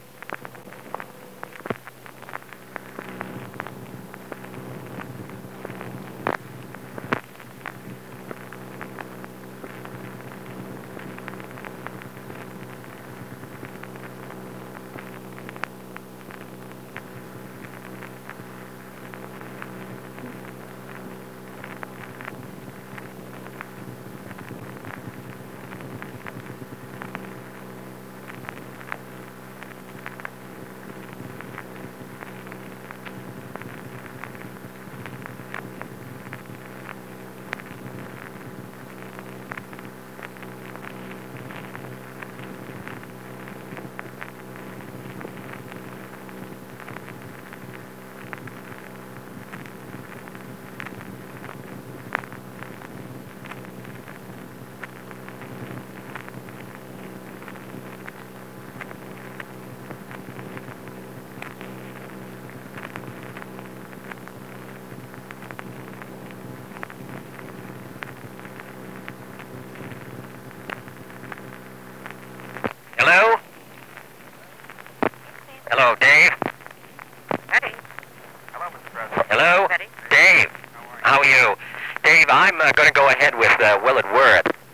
Conversation with David McDonald
Secret White House Tapes | John F. Kennedy Presidency Conversation with David McDonald Rewind 10 seconds Play/Pause Fast-forward 10 seconds 0:00 Download audio Previous Meetings: Tape 121/A57.